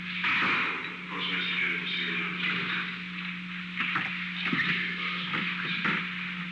Secret White House Tapes
Conversation No. 607-8
Location: Oval Office
The President met with Stephen B. Bull.